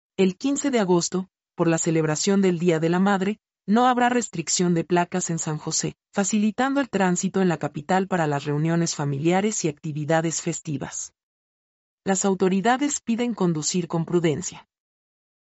mp3-output-ttsfreedotcom-57-1.mp3